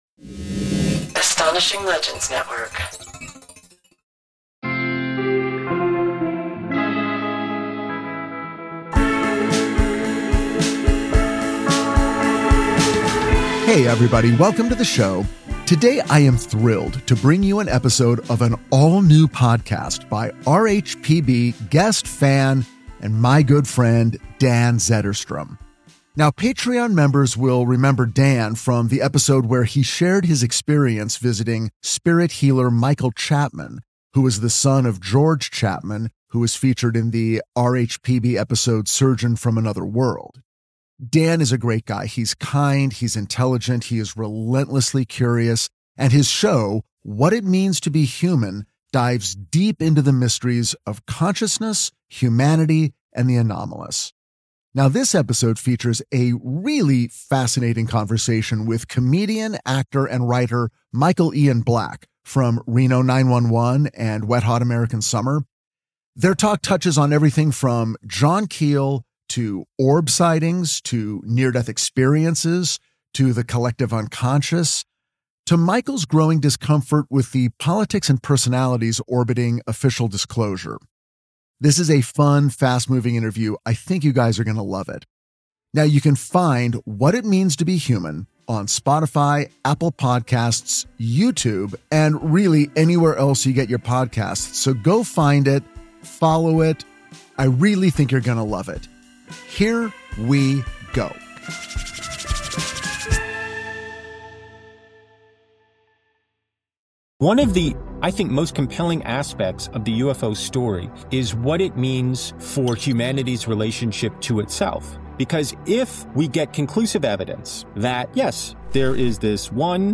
This is a fun and fast-moving interview that I think you guys will love.